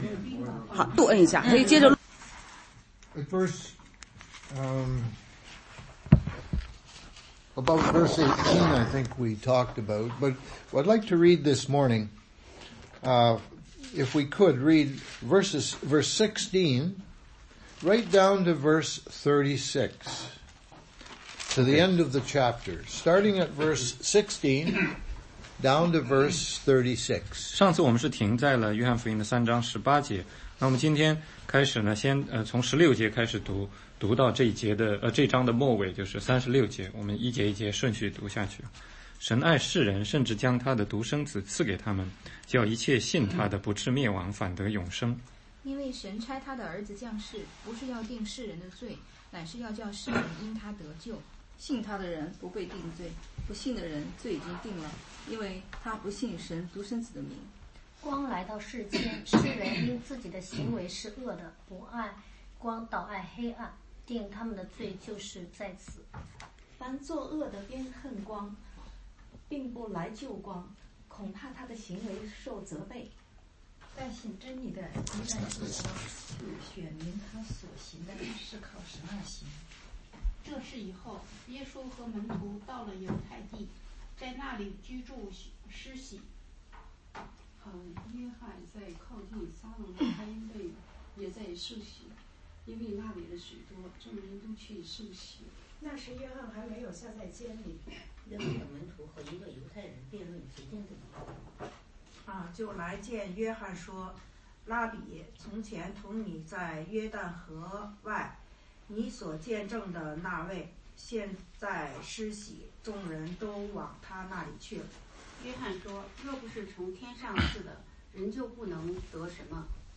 16街讲道录音 - 约翰福音3章16-36